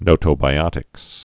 (nōtō-bī-ŏtĭks)